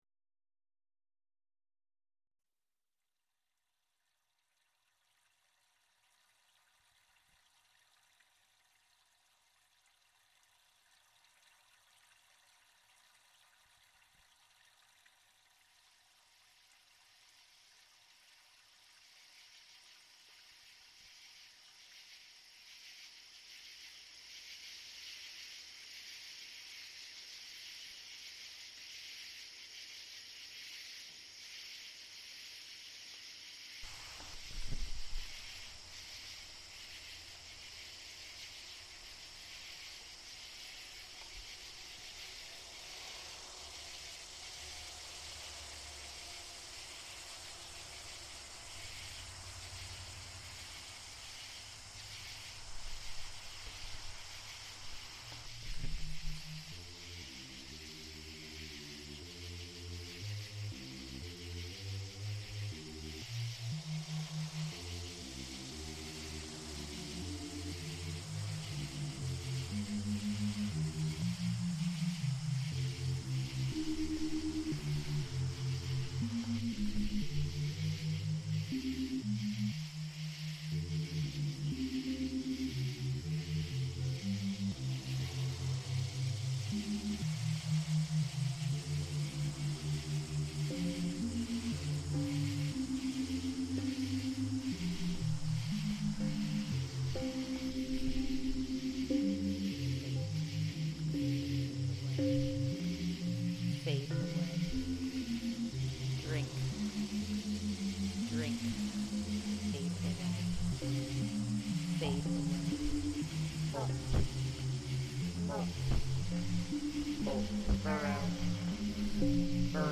Cicadas at Dingmans Ferry reimagined